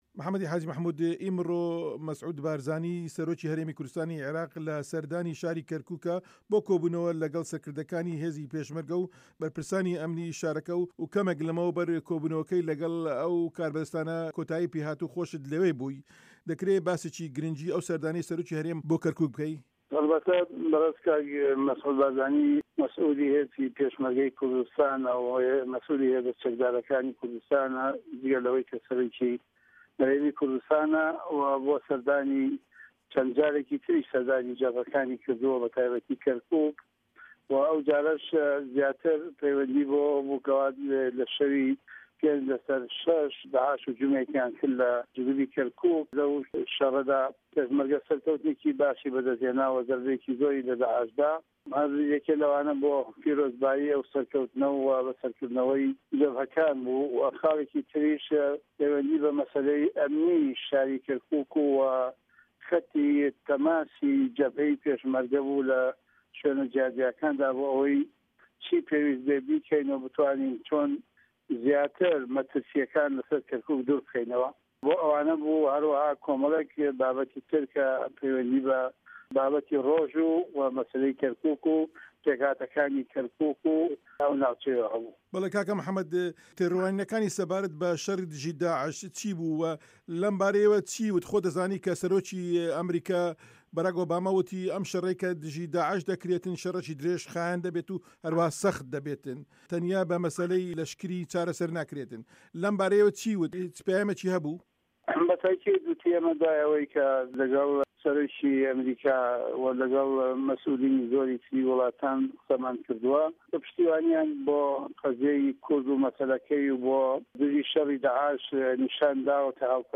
هه‌رێمه‌ کوردیـیه‌کان - گفتوگۆکان
وتووێژ لەگەڵ محەمەد حاجی مەحمود